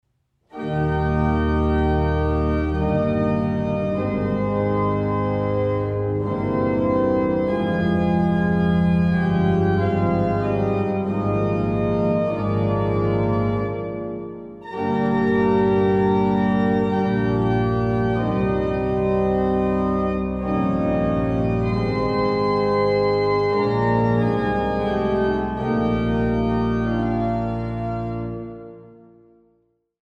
Stadtkirche St. Petri zu Löbejün